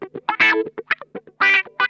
Index of /90_sSampleCDs/Zero G - Funk Guitar/Partition B/VOLUME 021
THROATWAH 5R.wav